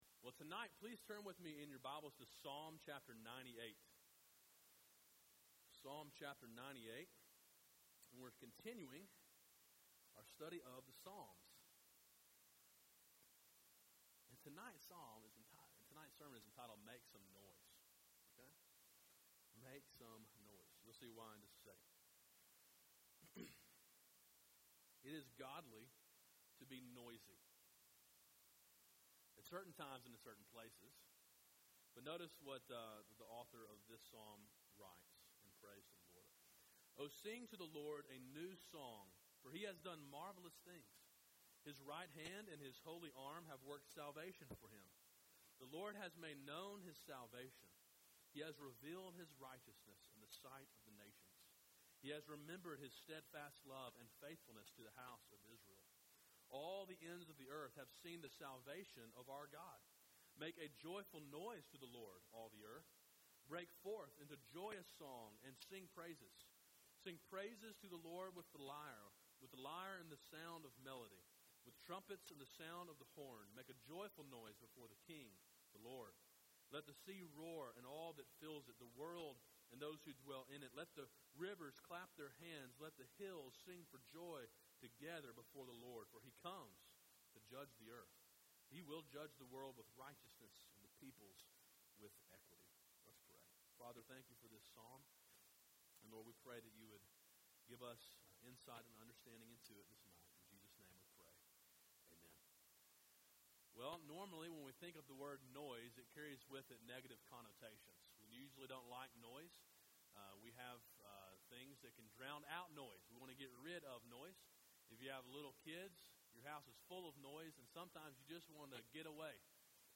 Sermon Audio: “Make Some Noise” (Psalm 98) – Calvary Baptist Church